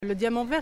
uitspraak Le Diamant Vert.